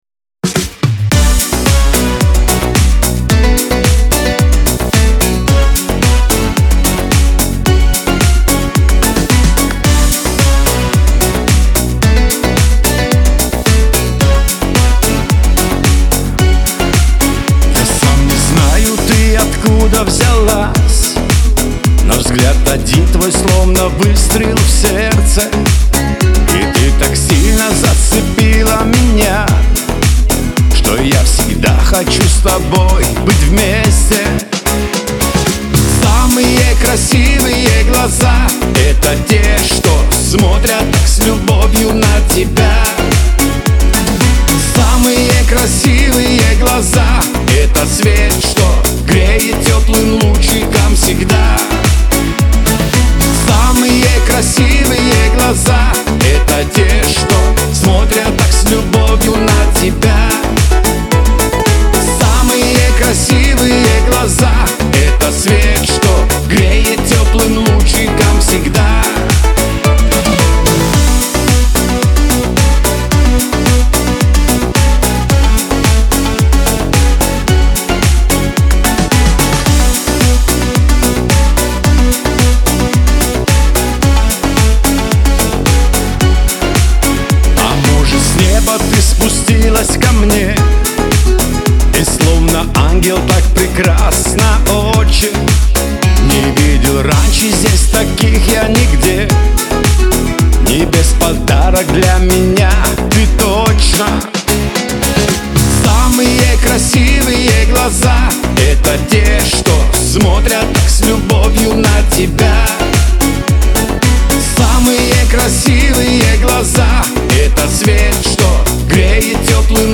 Веселая музыка
Лирика